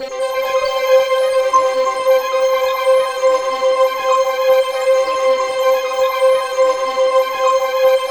Index of /90_sSampleCDs/USB Soundscan vol.13 - Ethereal Atmosphere [AKAI] 1CD/Partition C/05-COMPLEX